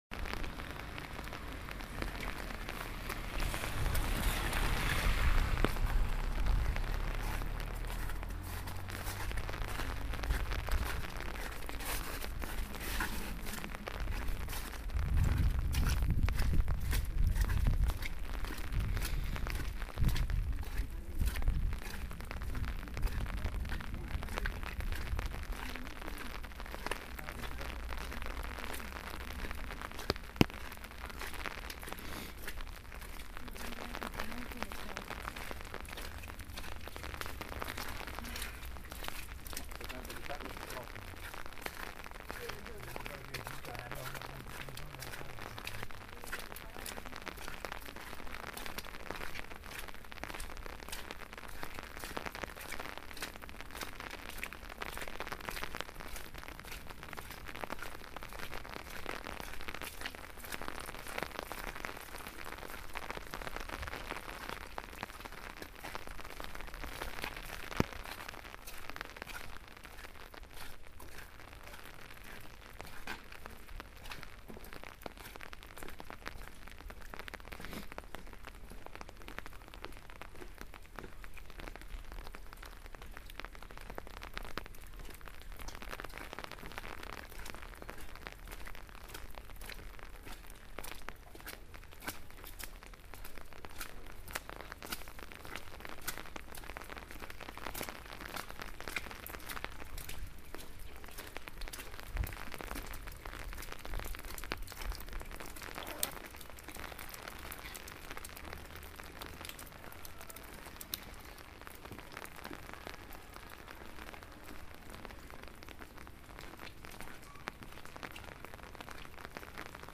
rain_funicolare.mp3